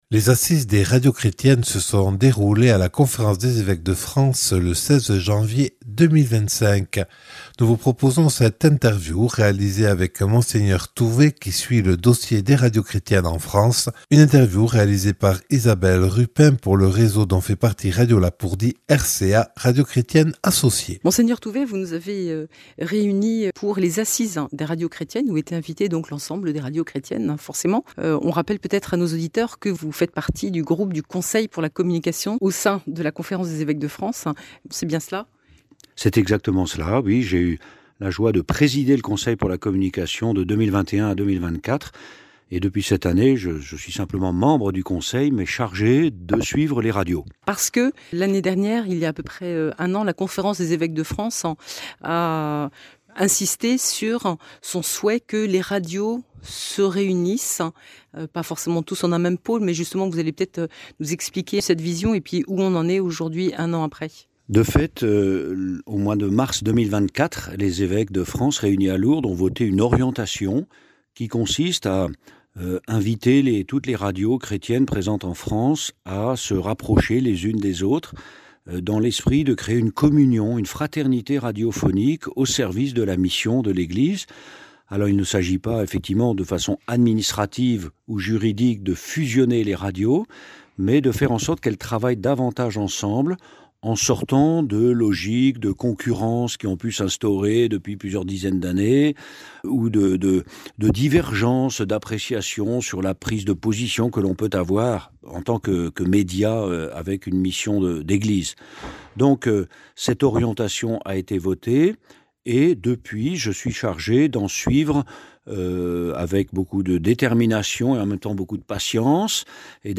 Avec Mgr François Touvet, chargé des radios chrétiennes par la Conférence épiscopale.
Accueil \ Emissions \ Infos \ Interviews et reportages \ Retour sur les Assises des Radios Chrétiennes le 16 janvier à (...)